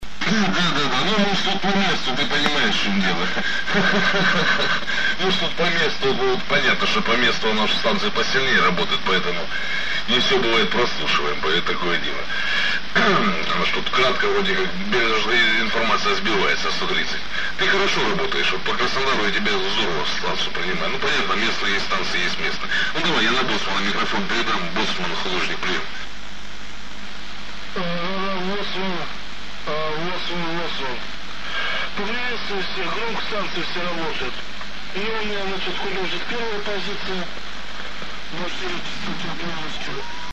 Слепил тут себе небольшой приемный тракт (а то снова скажут что никто ничего не делает) smile Очень хорошо принимает, чувствительности - море. По ощущениям - вот именно такой приемник я себе и хотел, выставил усиление в одно положение чтобы шумы по ушам не били, и сиди слушай.
Прием на тройке во вложении  wink